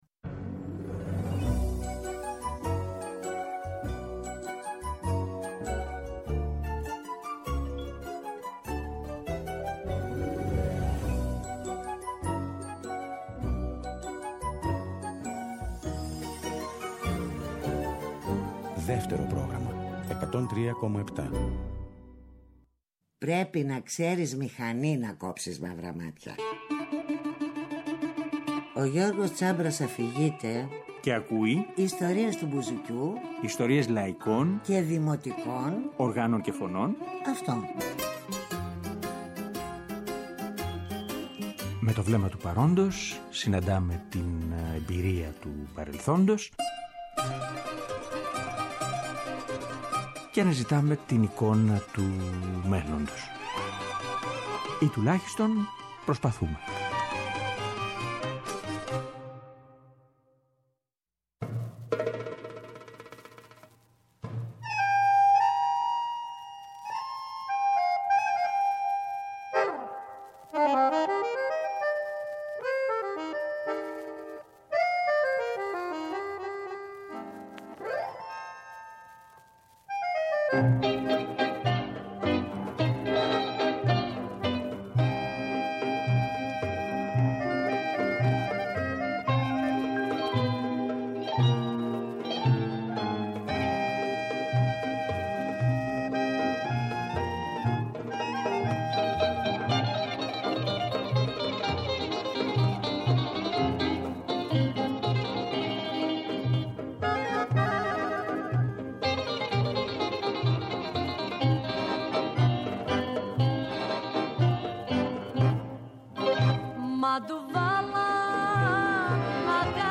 Πέμπτη 29 Σεπτεμβρίου, 9 με 10 το βράδυ, στο Δεύτερο Πρόγραμμα 103.7.